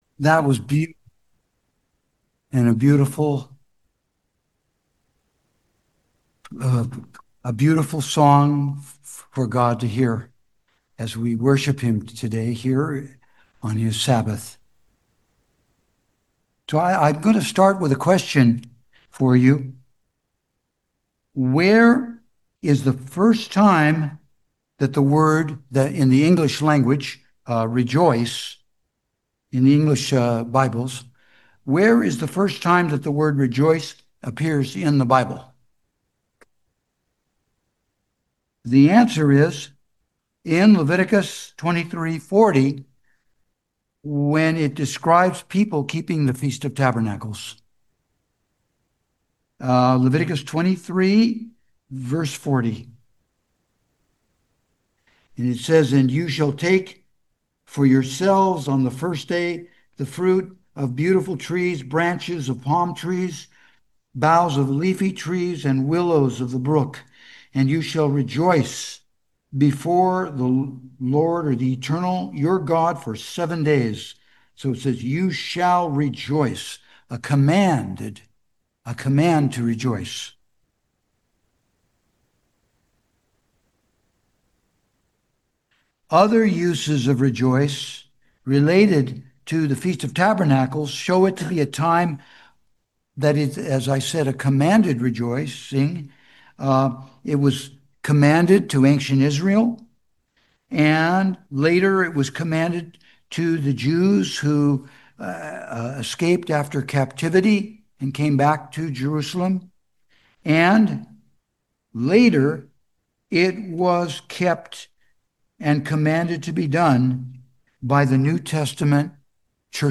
Sermons
Given in San Francisco Bay Area, CA San Jose, CA Petaluma, CA